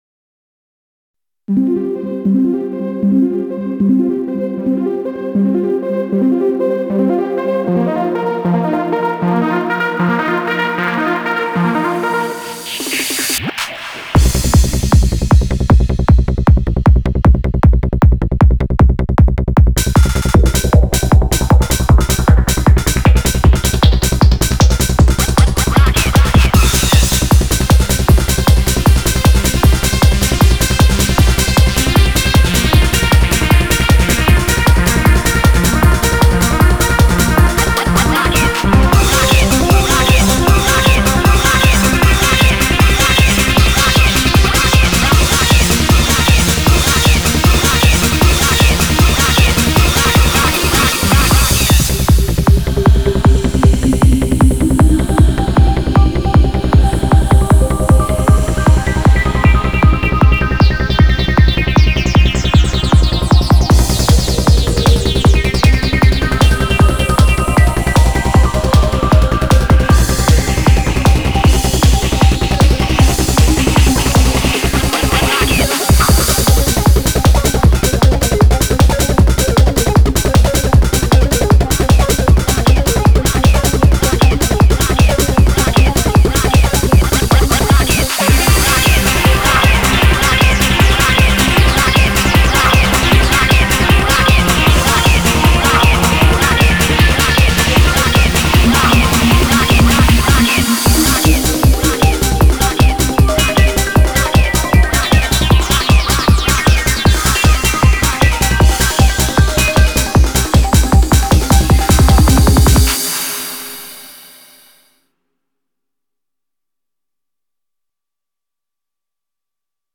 BPM0-154
Audio QualityPerfect (High Quality)